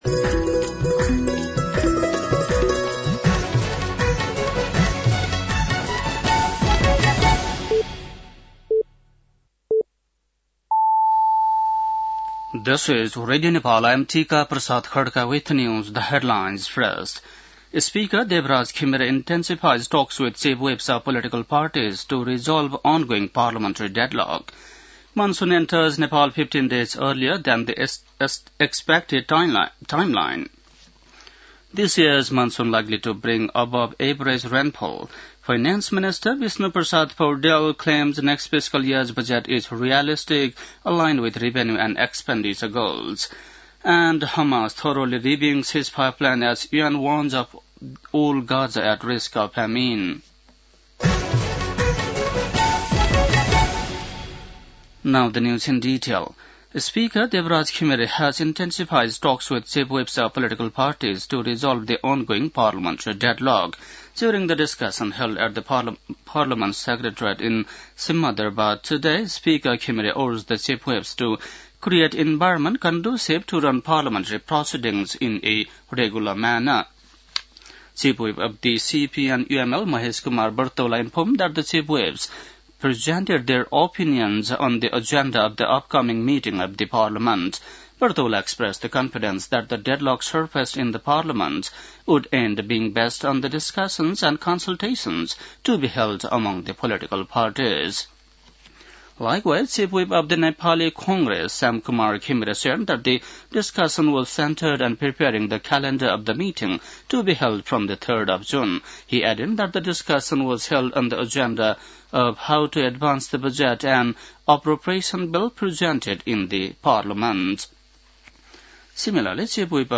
बेलुकी ८ बजेको अङ्ग्रेजी समाचार : १६ जेठ , २०८२
8-PM-English-NEWS-2-16.mp3